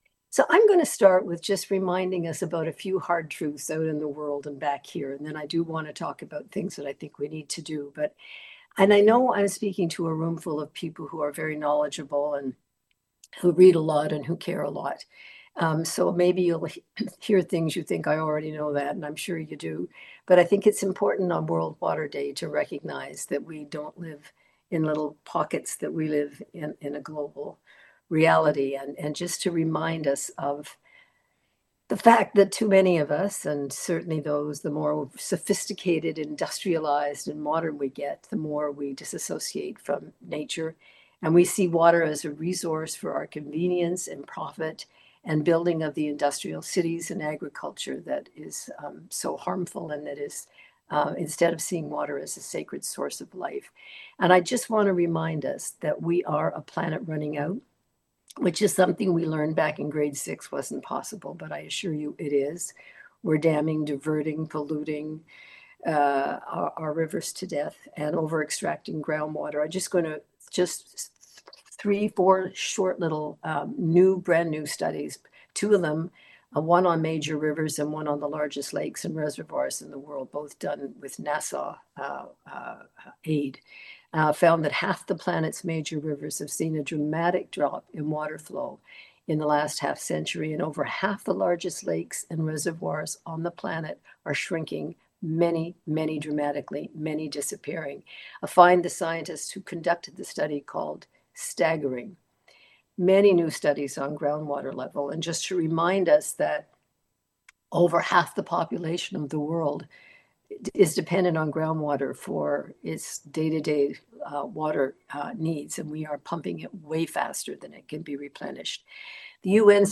Listen: Maude Barlow Making Waves: WKWC World Water Day Symposium — WKWC
Maude Barlow speaks about her new book, the global water crisis, the ongoing consequences of free trade, financialization of Nature, the threat to Canada’s fresh water by AI data centres, and “Wise Hope.”